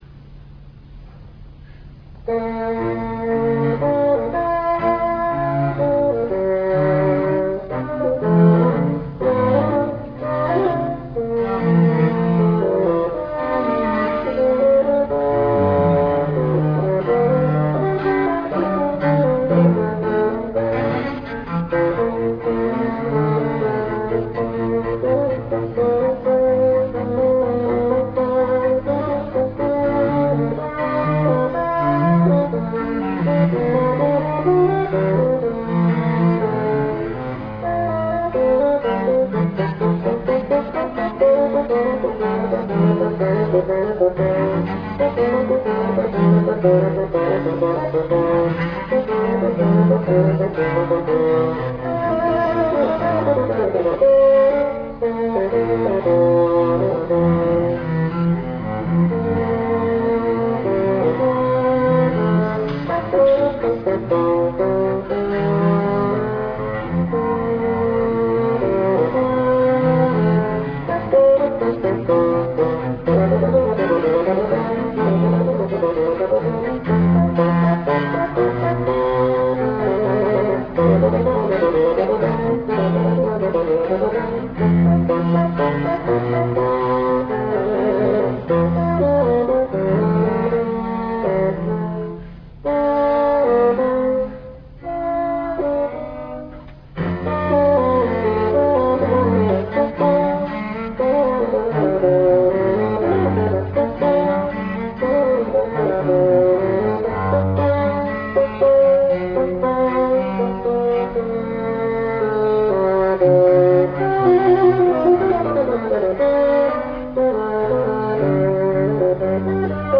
１９９９年８月８日・八幡市文化センター小ホールにて行われた八幡市民オーケストラ室内楽の集い〜真夏の真昼のコンサート〜での演奏。
（PCM 11,025 kHz, 8 ビット モノラル/RealPlayer3.0以上でお聞き下さい）